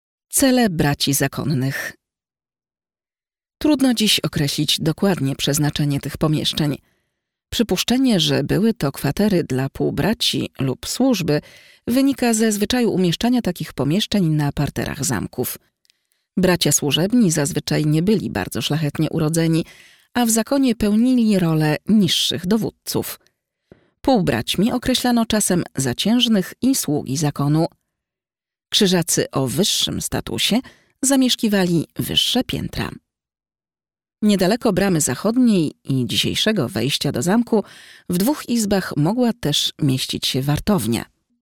audioprzewodnik_1-cele-braci-zakonnych.mp3